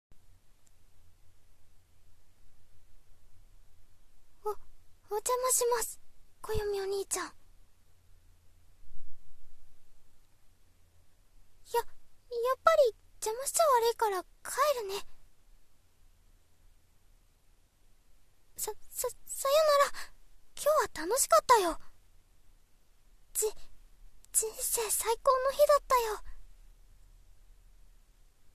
千石撫子【声真似】